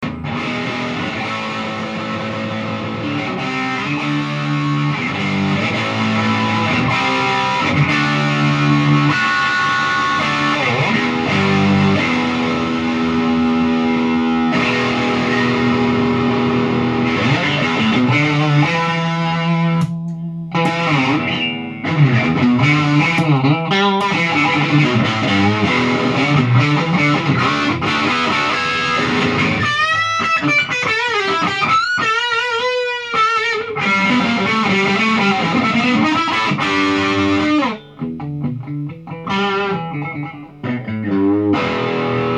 録音したMDから雑音のひどいものを取り除き、アップだば。